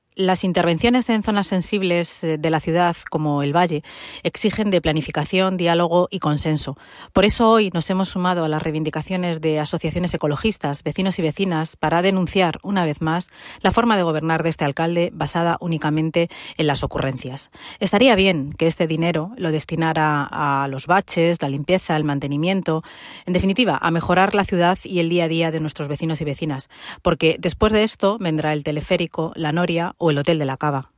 Noelia-de-la-Cruz_concentracion-arba.mp3